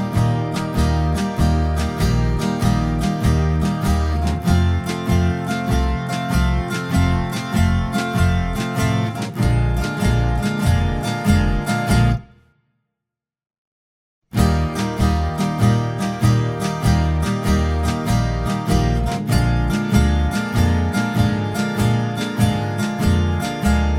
no Backing Vocals Oldies (Male) 2:16 Buy £1.50